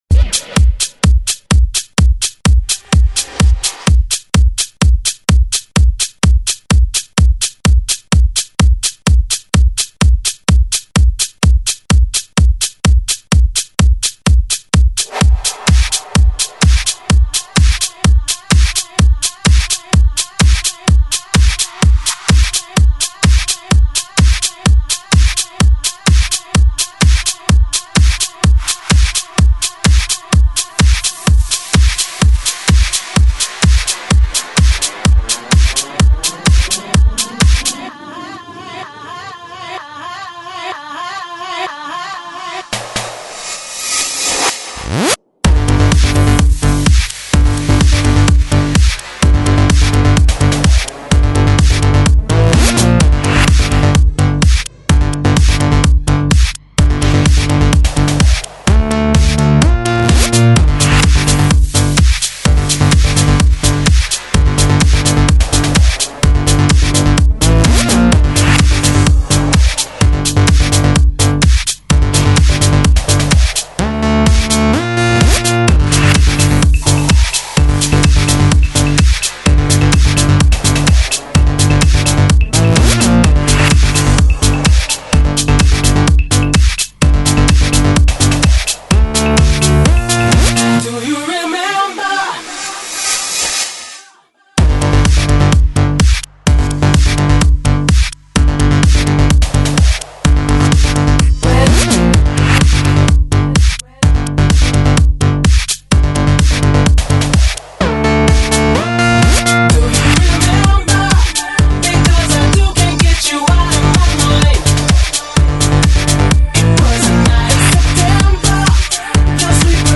Офигенный, просто супер микс...
Жанр:Electro/House